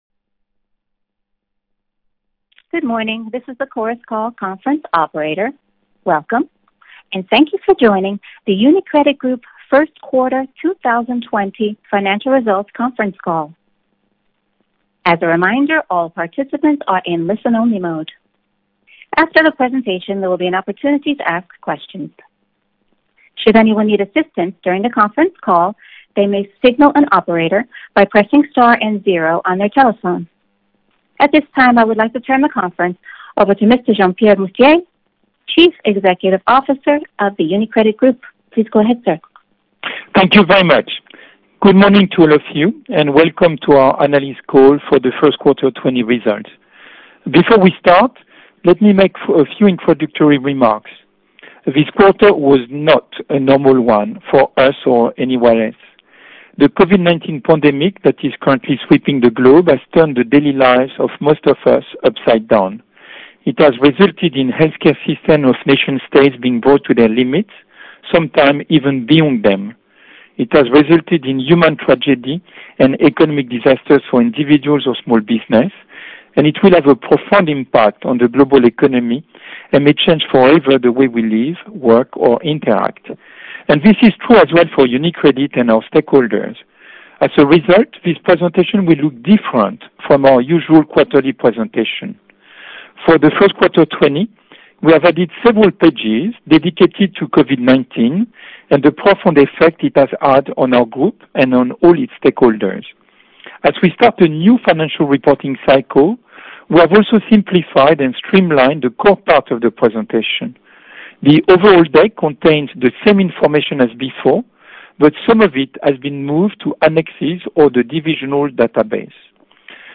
Presentazione dei risultati di Gruppo 1Q20 (pubblicazione risultati e conference call)